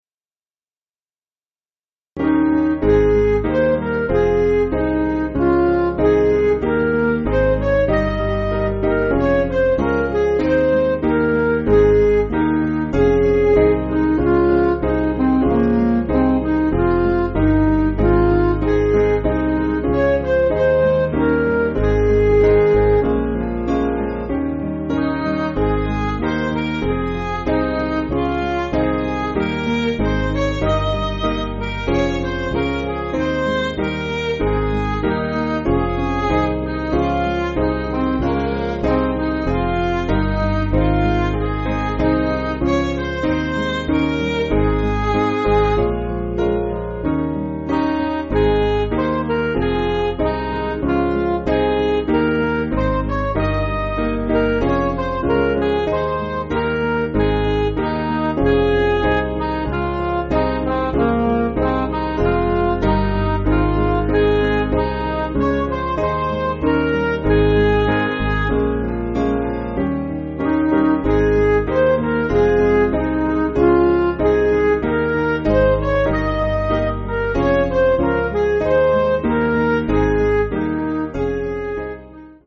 Piano & Instrumental
(CM)   6/Ab